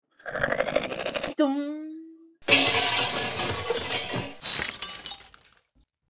Arrow Pull Back and Twang and Crash
Category 🤣 Funny
Accent Arrow Break Cartoon Comedy Crash Funny Glass sound effect free sound royalty free Funny